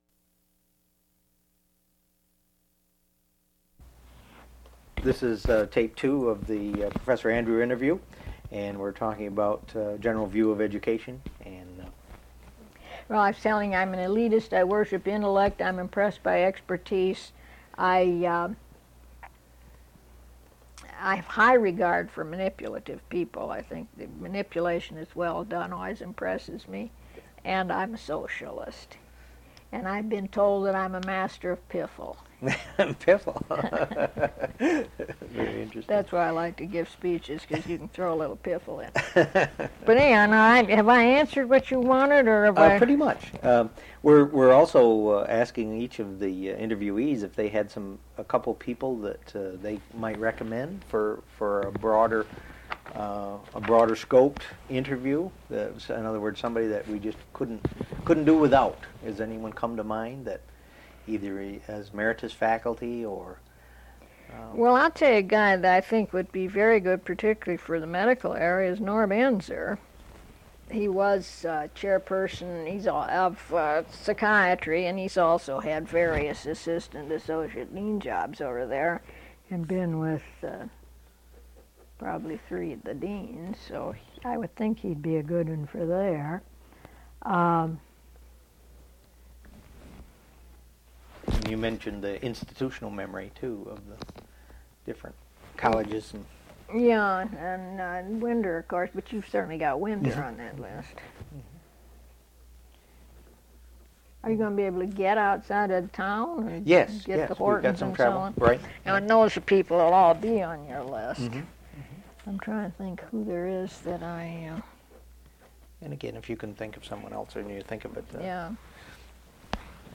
Original Format: Audiocassettes
Relation: Sesquicentennial Oral History Project